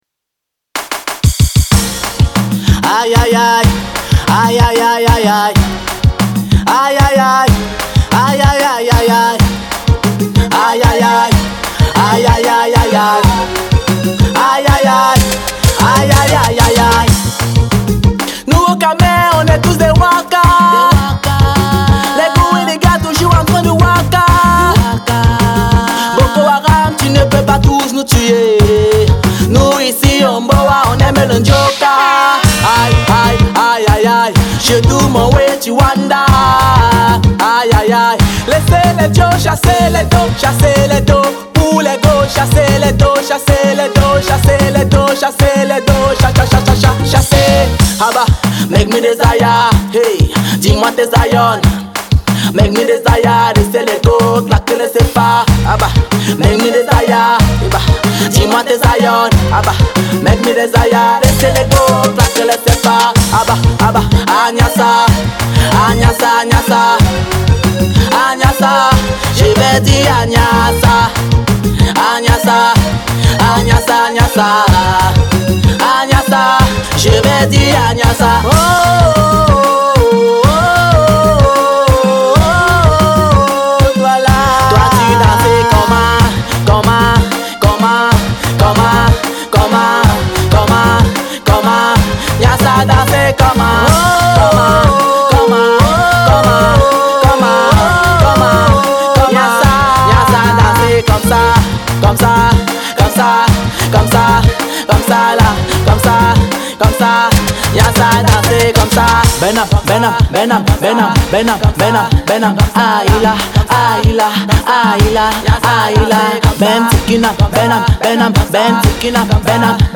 afropop et RnB
Comique, festif et coloré